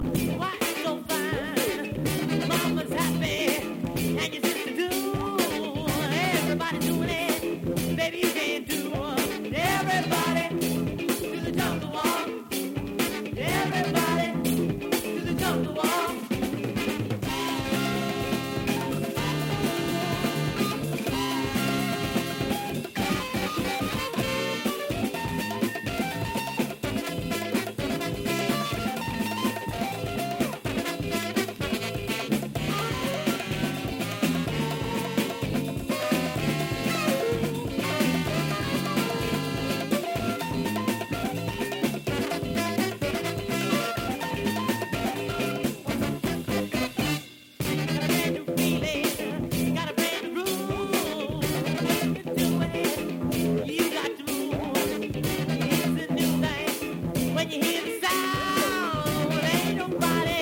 this is a double header of mid ‘70s funk fire.
the rock-funk